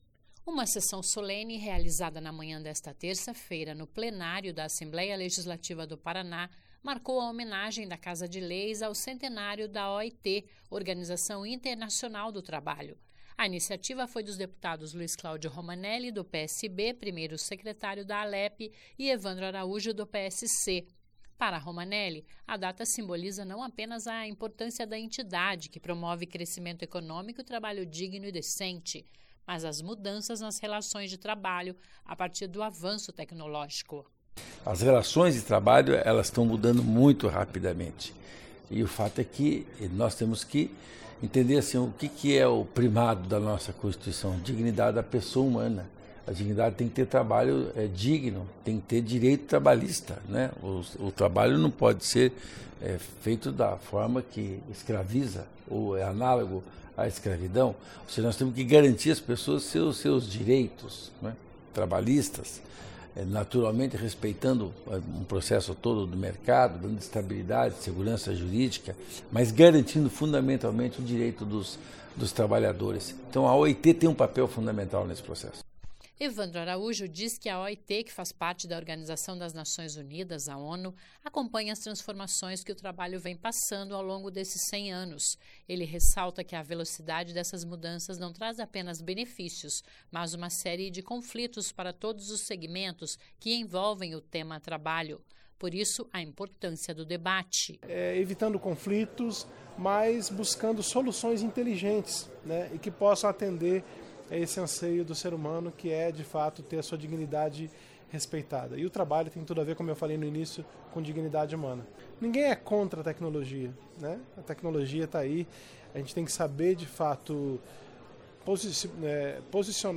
Uma sessão solene realizada na manhã desta terça-feira (27) no Plenário da Assembleia Legislativa do Paraná (Alep), marcou a homenagem da Casa de Leis ao centenário da OIT (Organização Internacional do Trabalho).